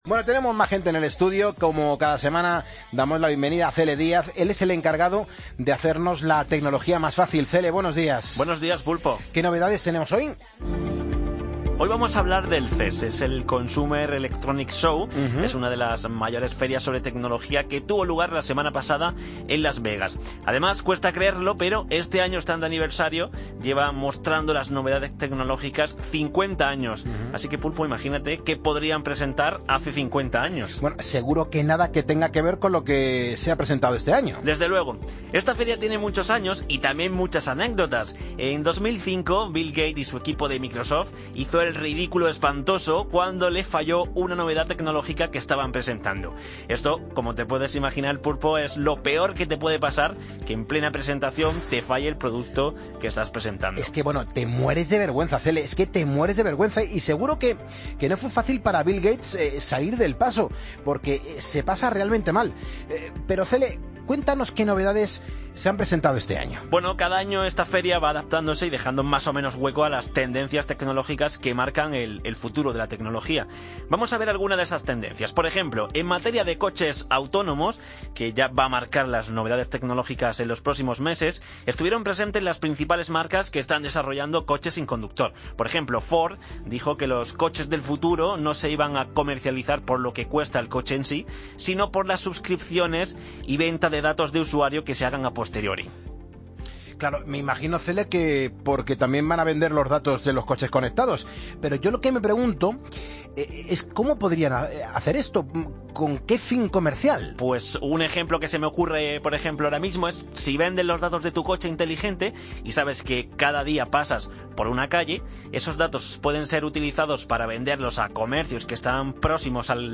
Entrevistas en Poniendo las calles